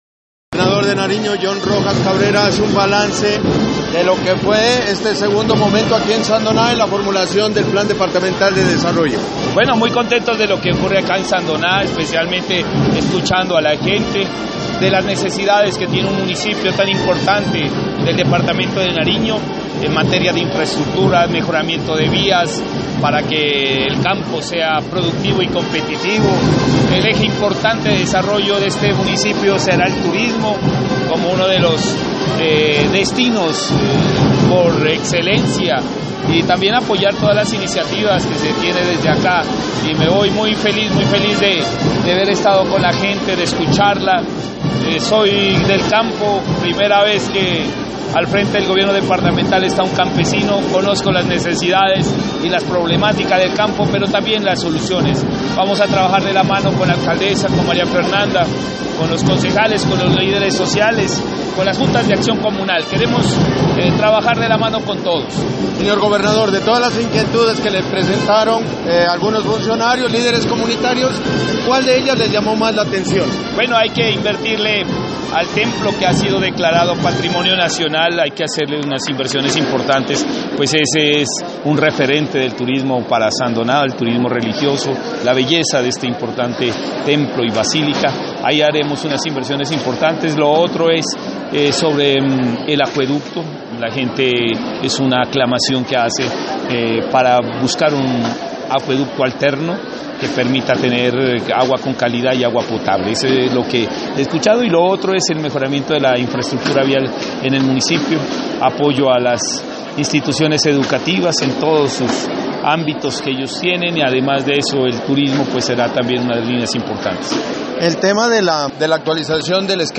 Entrevista con el gobernador Jhon Rojas Cabrera: